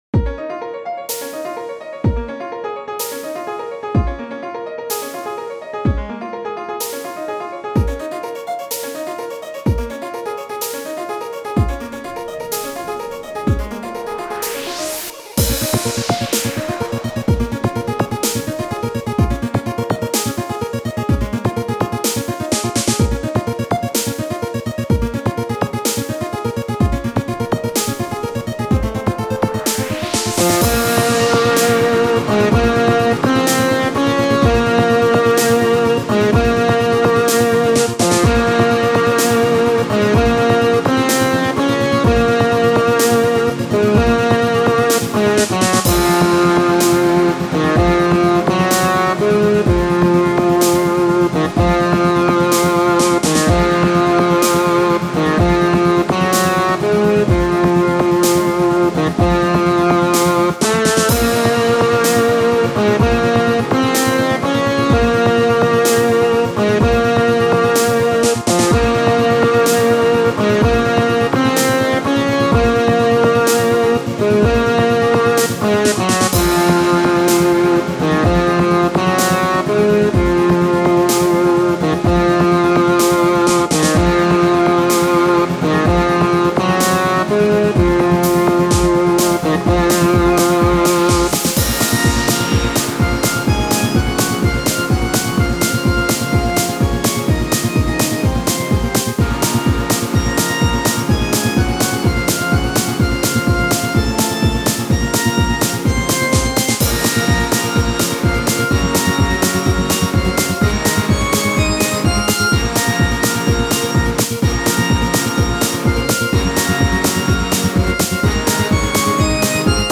これもボス曲。